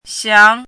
chinese-voice - 汉字语音库
xiang2.mp3